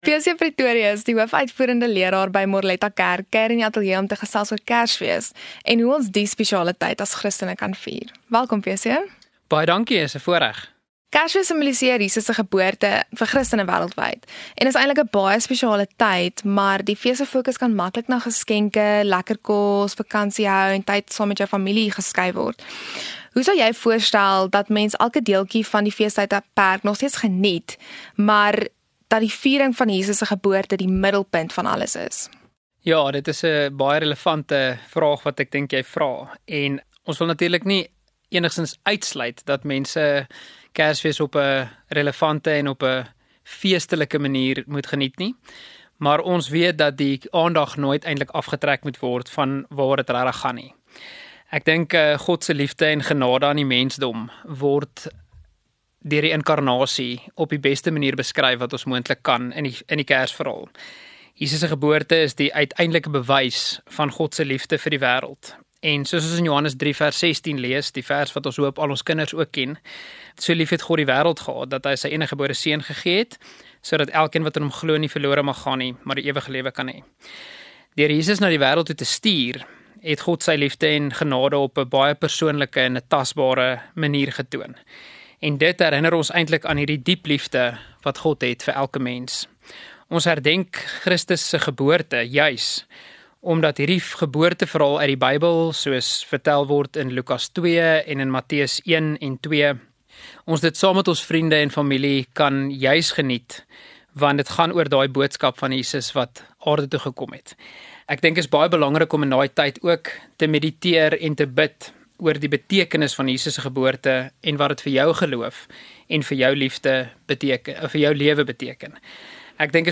Maroela Media gesels met interessante mense in die ateljee.